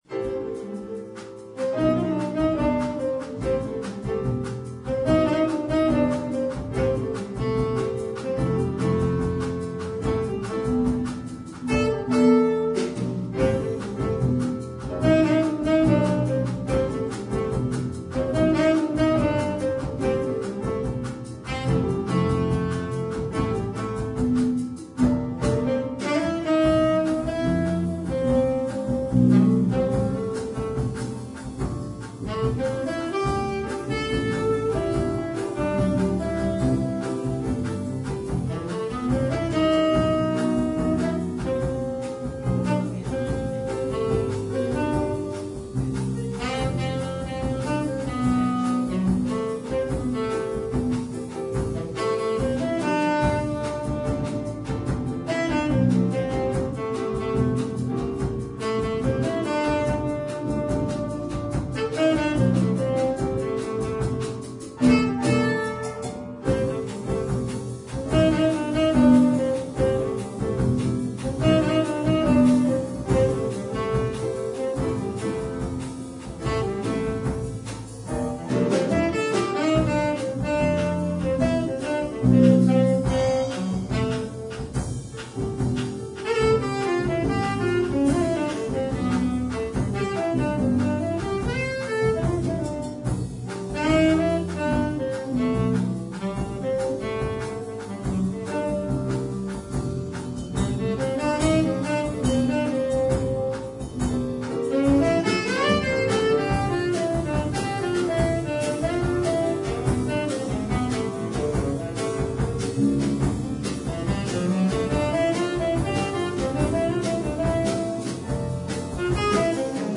Bearbeitet für dreistimmigen Chor und Klavier